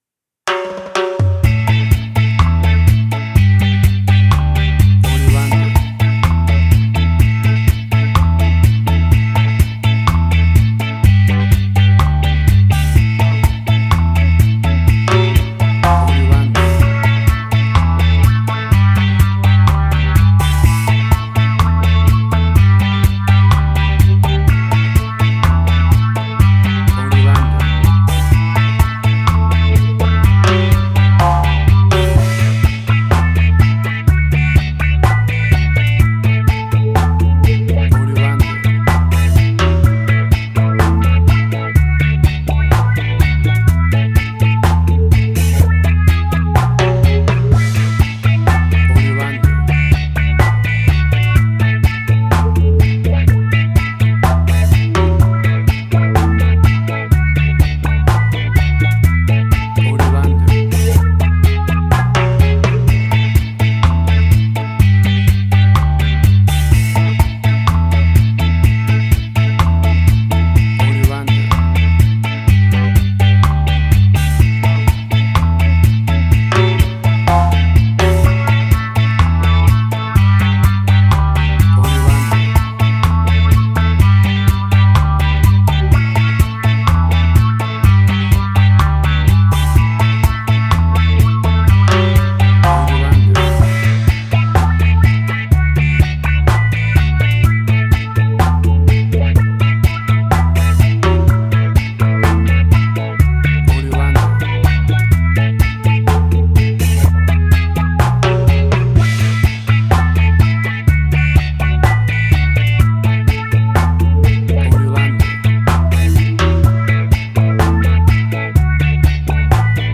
Classic reggae music with that skank bounce reggae feeling.
WAV Sample Rate: 16-Bit stereo, 44.1 kHz
Tempo (BPM): 62